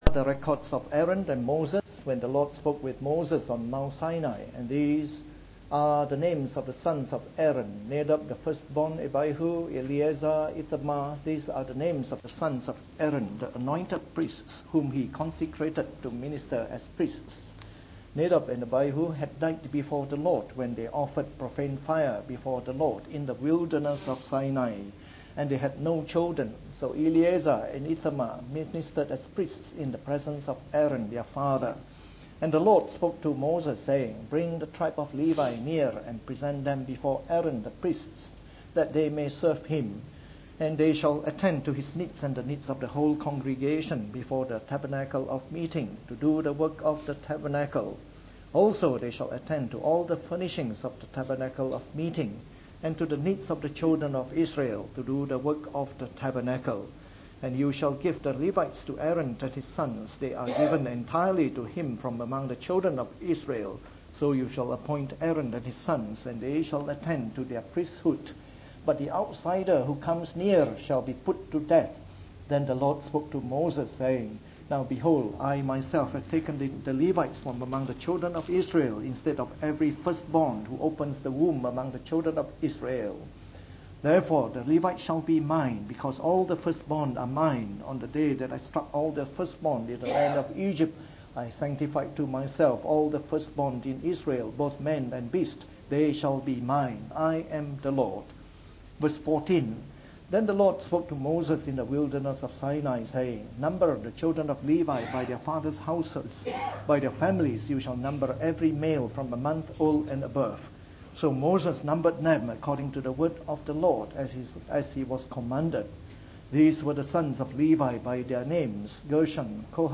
From our new series on the “Book of Numbers” delivered in the Morning Service.